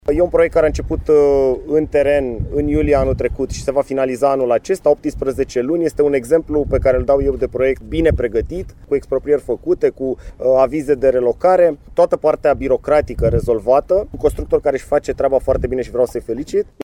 Ministrul Transporturilor s-a arătat  mulțumit de compania care efectuează și lucrările pentru porțiunea de 5 km de autostradă și 5 km drum de legătură între Aeroportul Transilvania și municipiul Tg.Mureș: